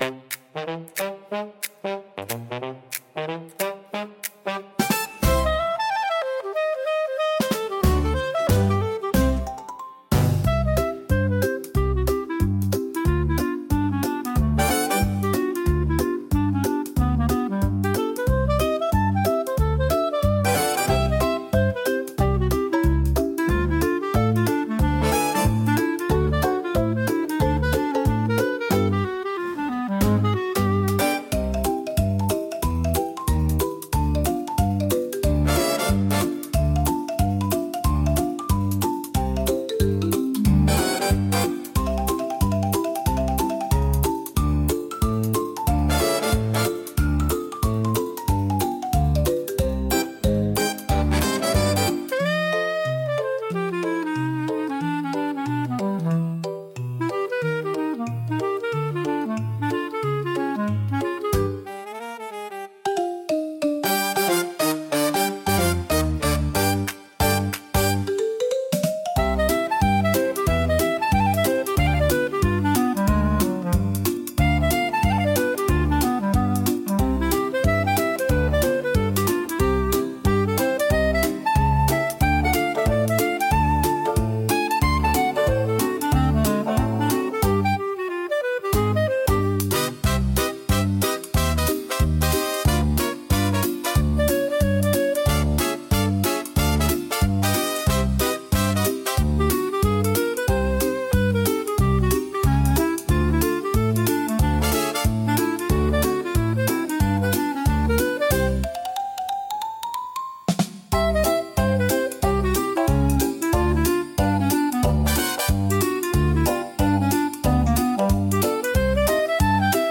聴く人に軽やかで楽しい気分を届け、明るく気楽な空気を作り出します。